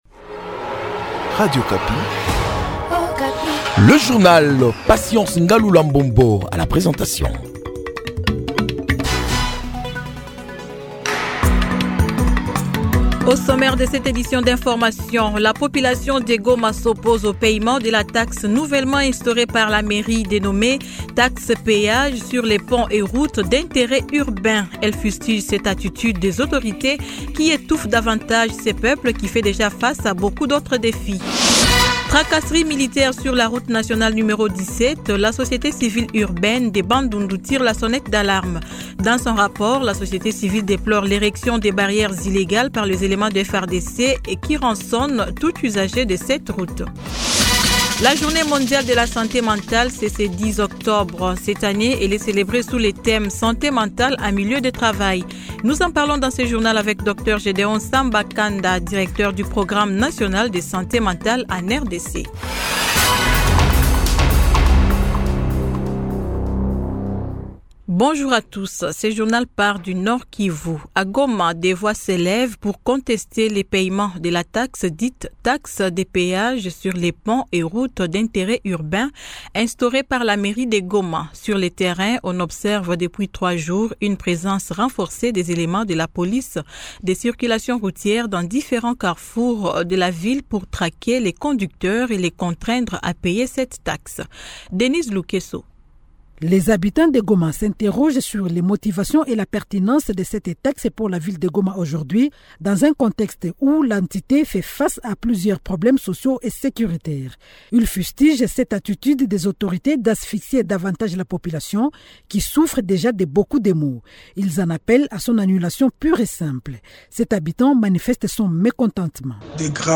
Journal 8H 00